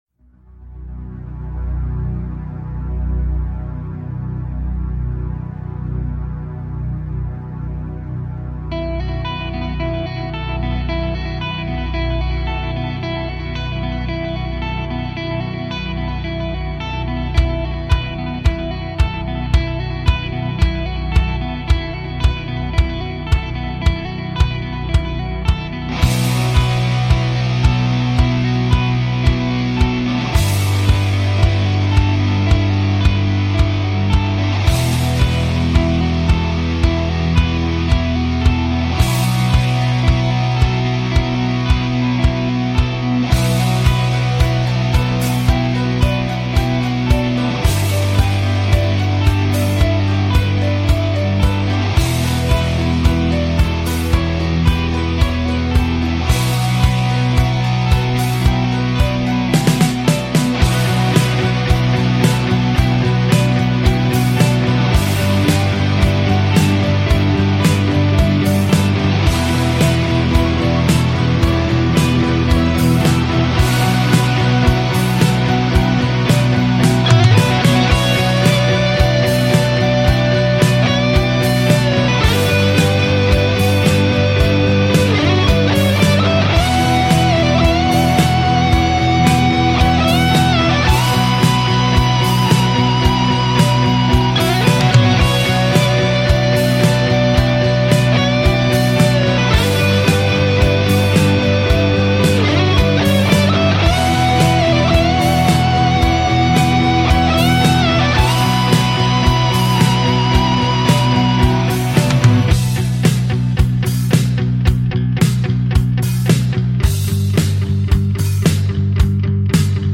Guitar Tab || Tutorial